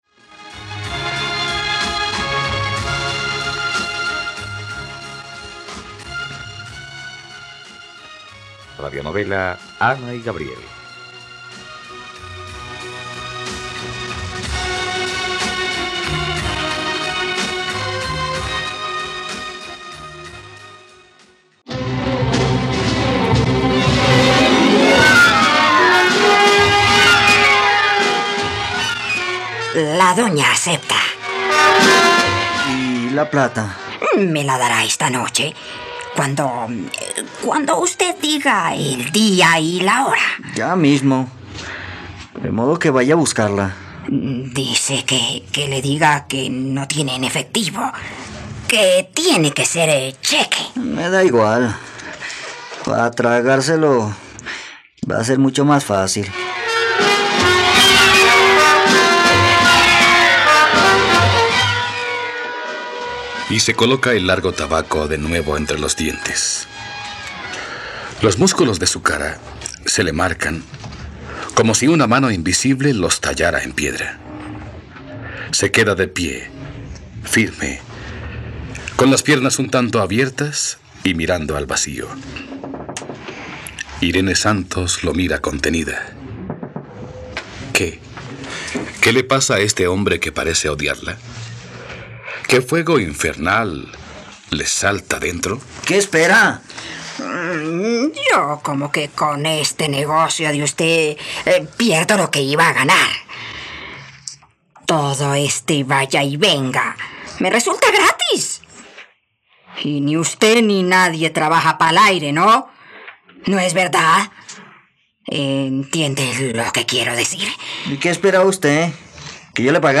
..Radionovela. Escucha ahora el capítulo 121 de la historia de amor de Ana y Gabriel en la plataforma de streaming de los colombianos: RTVCPlay.